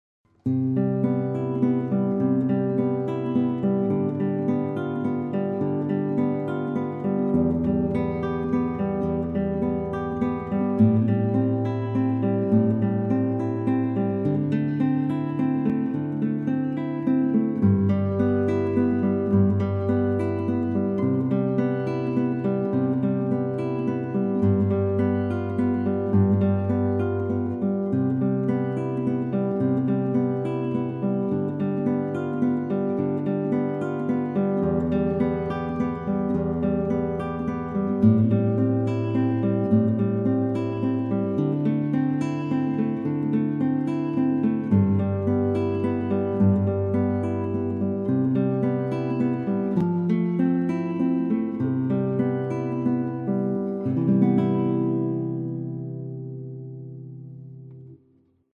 Solo gitaar
• Instrumenten: Gitaar Solo